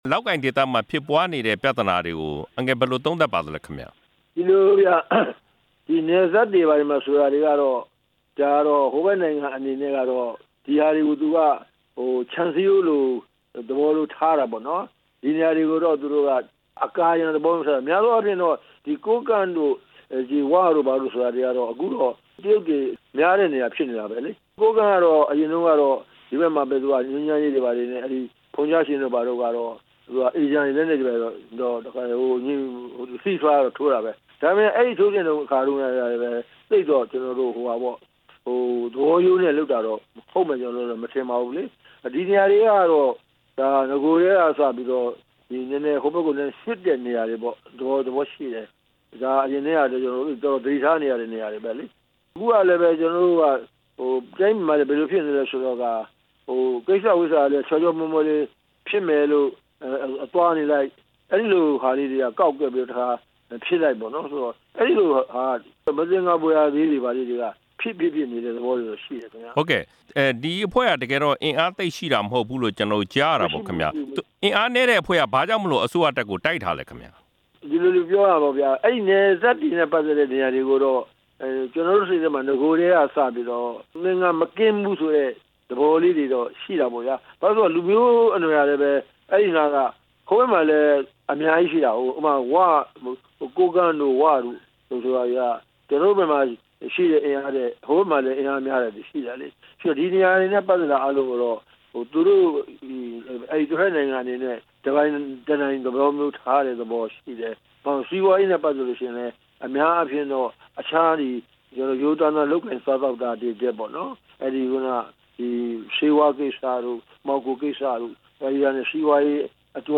ကိုးကန့်တိုက်ပွဲတွေနဲ့ ပတ်သက်ပြီး ဦးတင်ဦးကို မေးမြန်းချက်